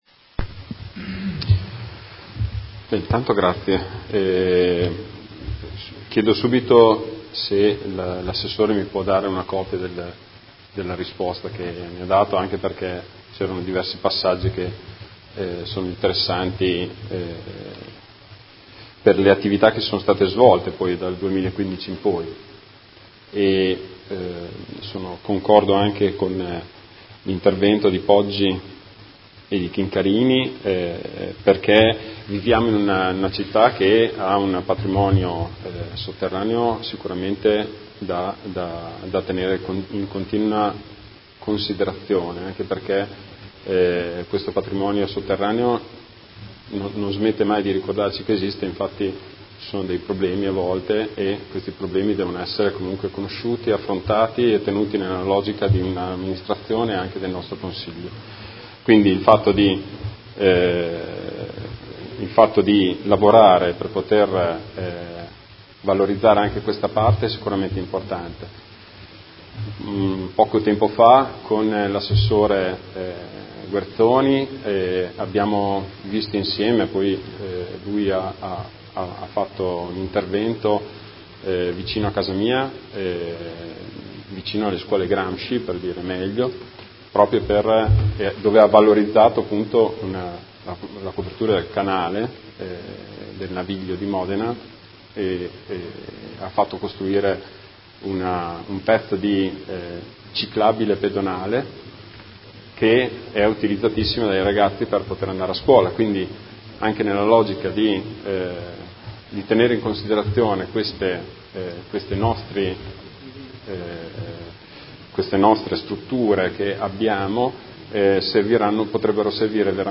Seduta del 15/11/2018 Replica a risposta Assessore Guerzoni. Interrogazione del Gruppo Consiliare Movimento cinque Stelle avente per oggetto: Occupazione del sedime del Cavo Archirola (Argine).